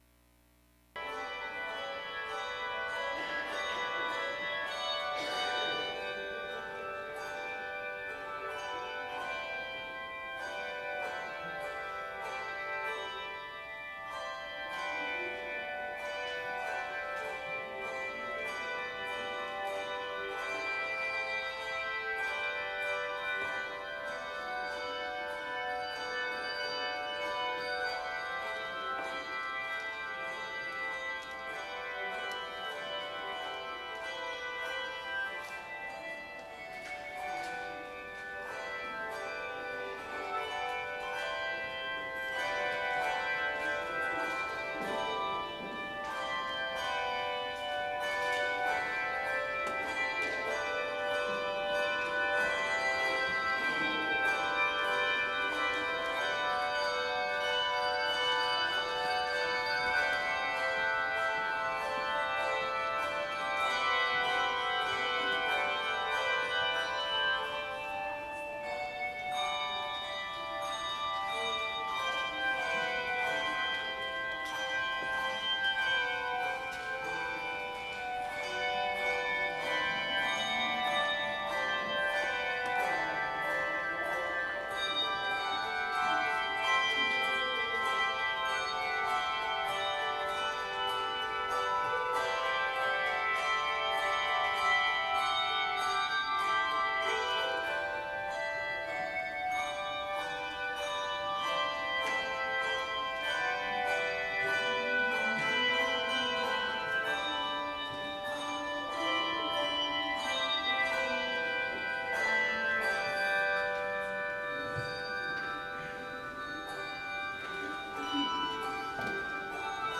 Worship Service Sunday May 25 2025